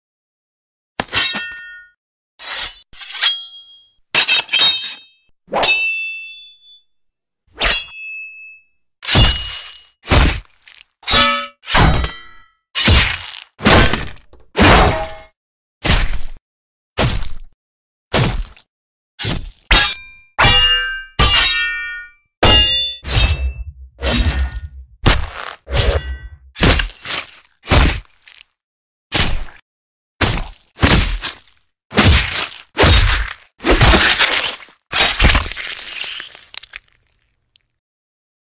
Tổng hợp tiếng Kiếm chém (Slice/Slash/Crash/Swoosh/etc)...